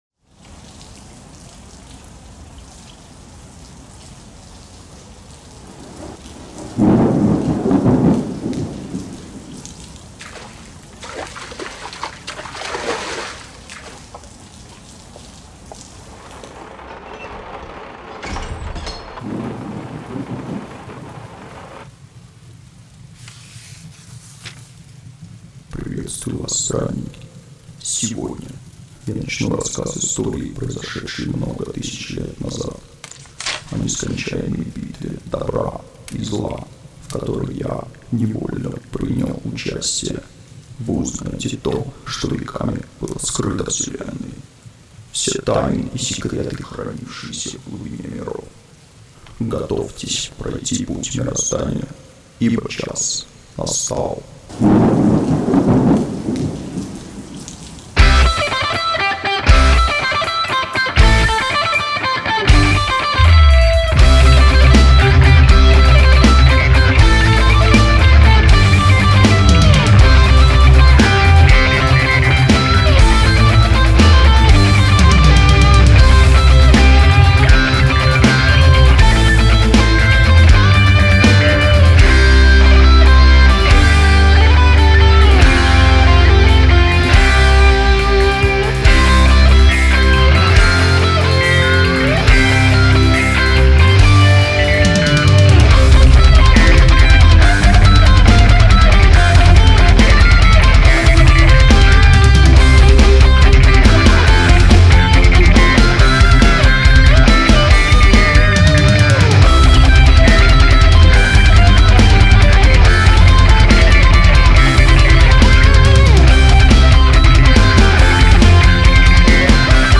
Инструментальный трек московской Power Metal группы